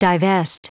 Prononciation du mot divest en anglais (fichier audio)
Prononciation du mot : divest